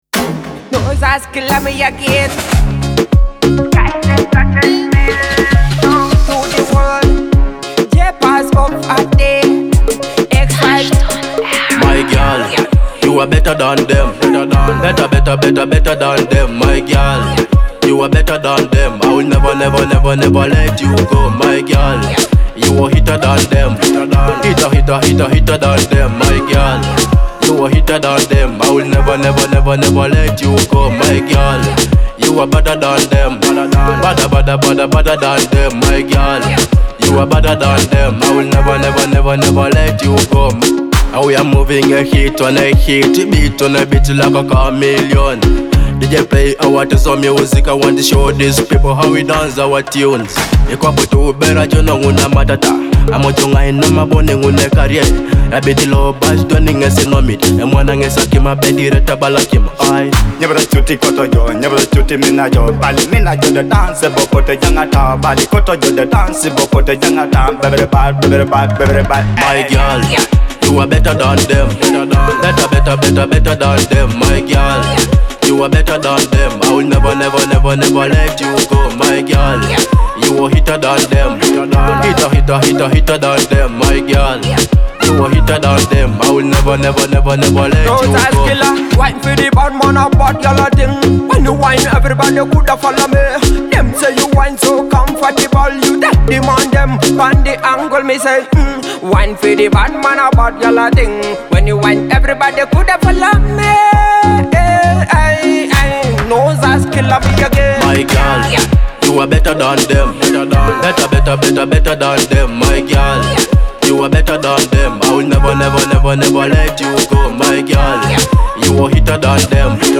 a fresh Ugandan Afrobeat/Afro-pop hit from the Teso region.